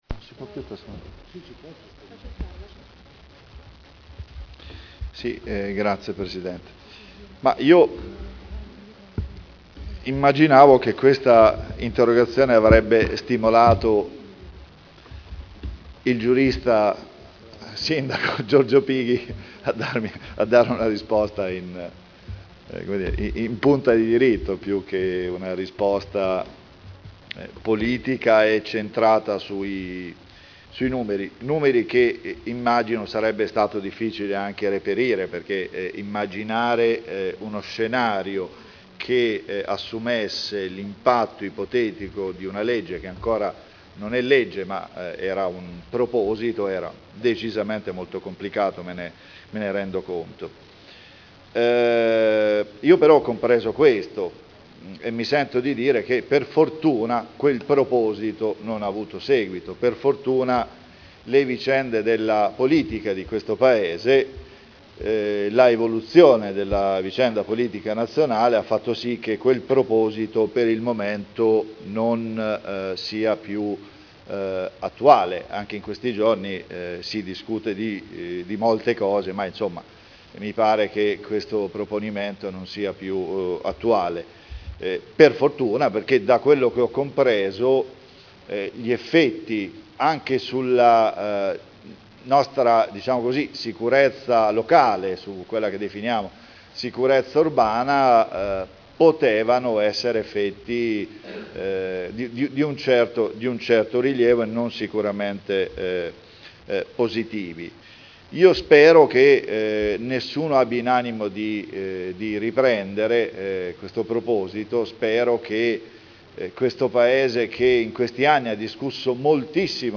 Paolo Trande — Sito Audio Consiglio Comunale
Replica a risposta Sindaco su Interrogazione dei consiglieri Trande e Urbelli (P.D.) avente per oggetto: “Legge sulla “Prescrizione breve”: quali conseguenze sono ipotizzabili sulla sicurezza del nostro territorio?” – Primo firmatario consigliere Trande (presentata l’1 aprile 2011 - in trattazione il 21.7.2011)